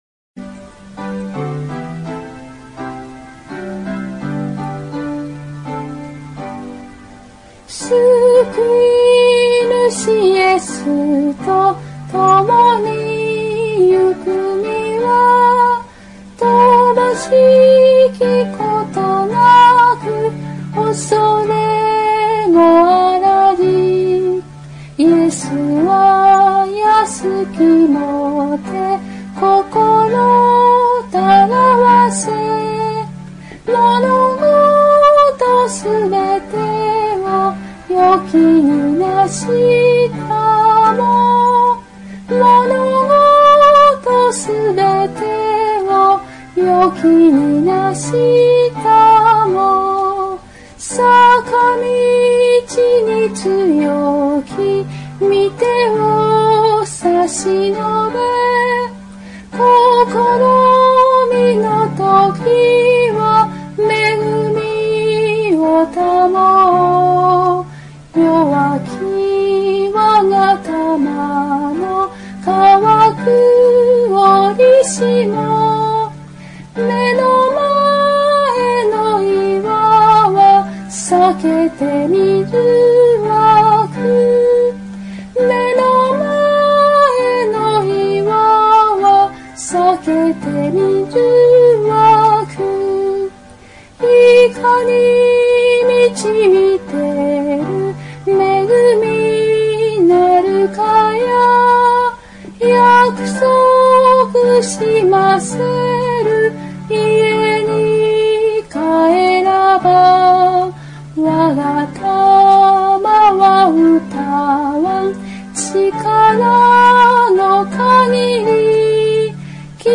唄